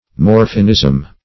Search Result for " morphinism" : The Collaborative International Dictionary of English v.0.48: morphinism \mor"phin*ism\, n. (Med.) A morbid condition produced by the excessive or prolonged use of morphine.
morphinism.mp3